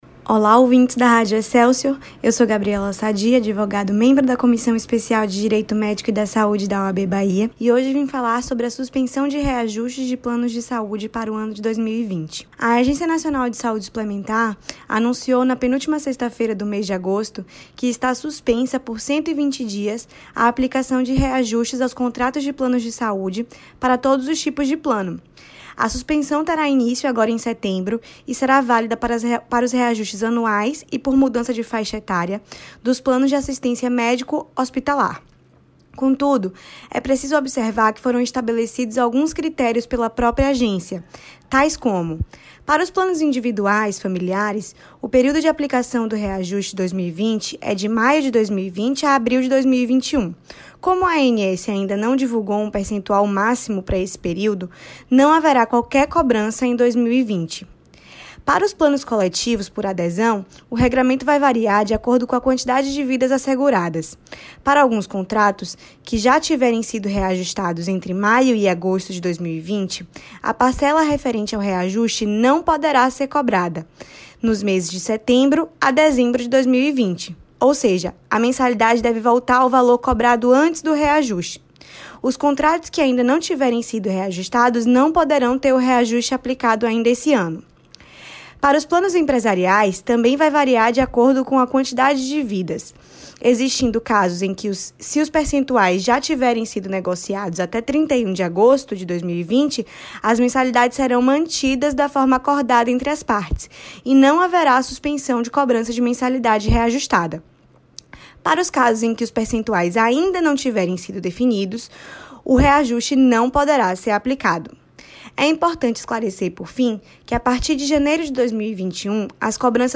na Radio Excelsior AM 84